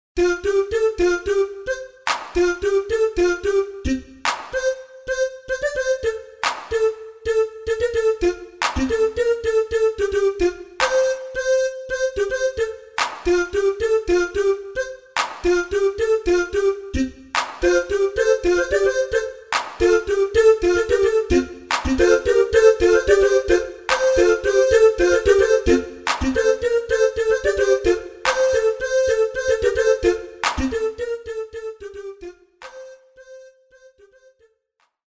CANONS